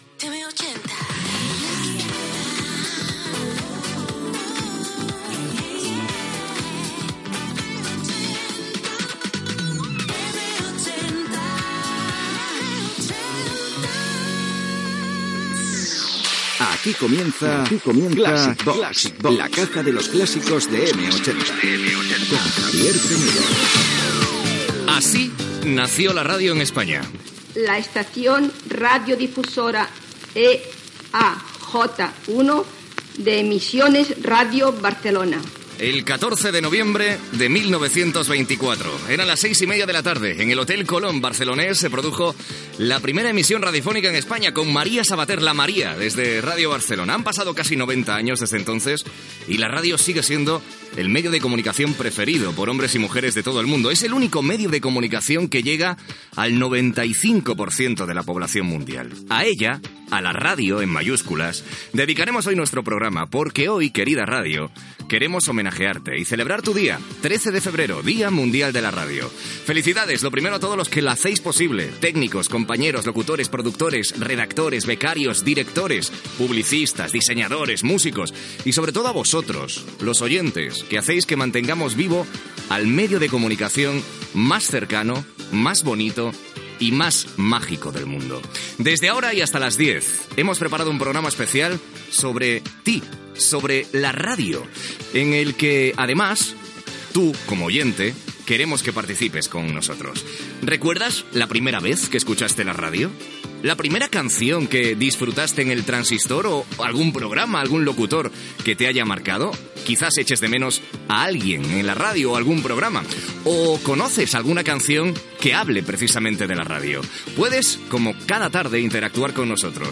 Indicatiu de l'emissora, careta del programa, inici de l'espai del Dia Mundial de la Ràdio de 2014, amb salutacions d'alguns professionals de la ràdio.
Musical